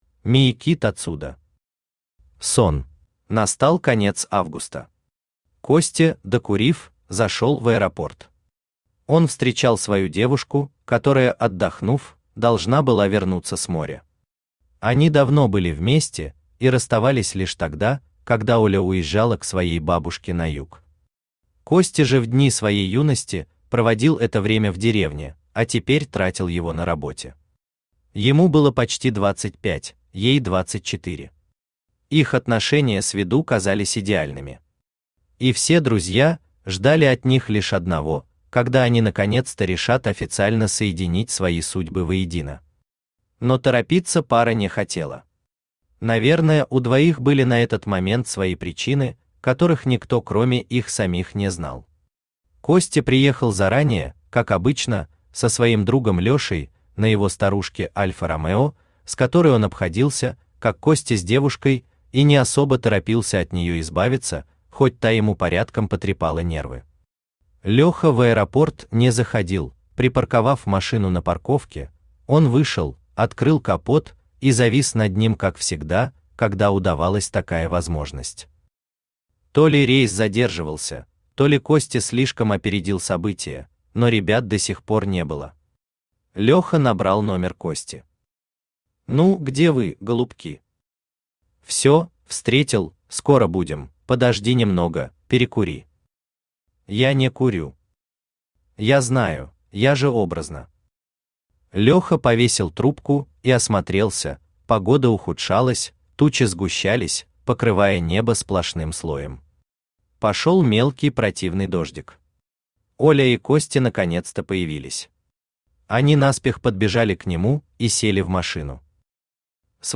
Аудиокнига Сон | Библиотека аудиокниг